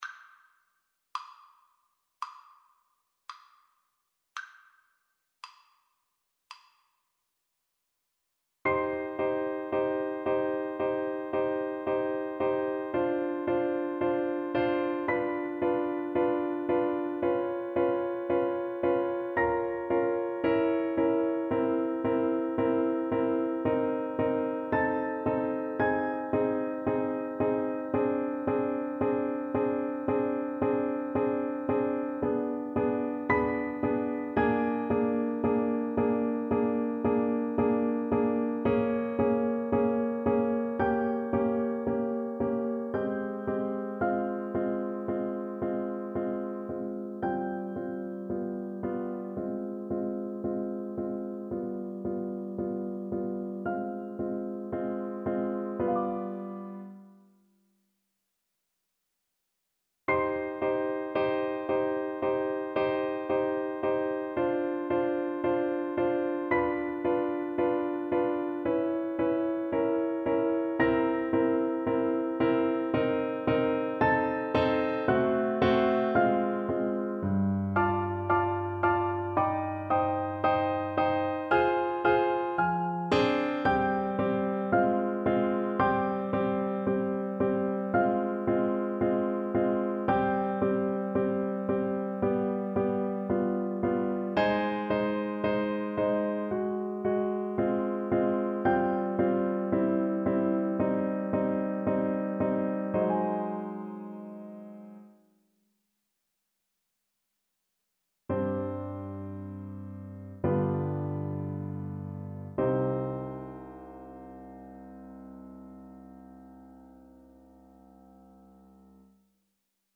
Largo = 56
4/4 (View more 4/4 Music)
G4-Eb6
Classical (View more Classical Trumpet Music)